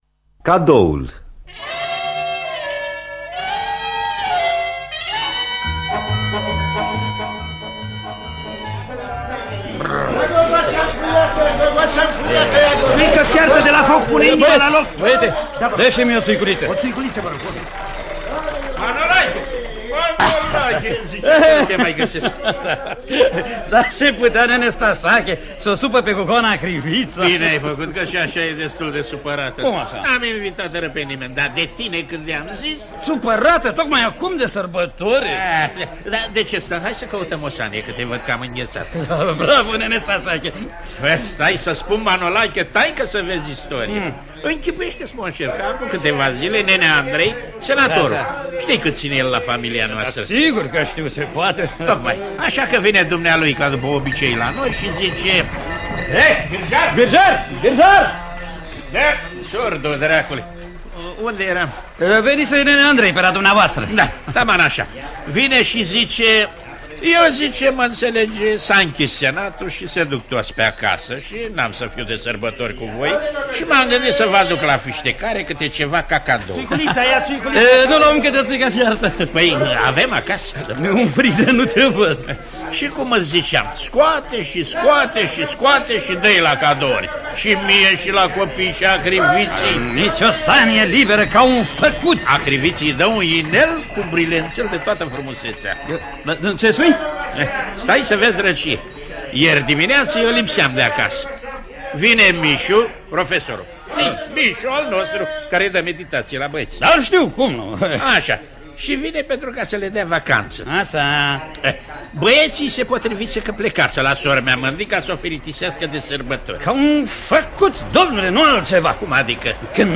În distribuţie: Alexandru Giugaru, Tudorel Popa, Carmen Stănescu, Damian Crâşmaru.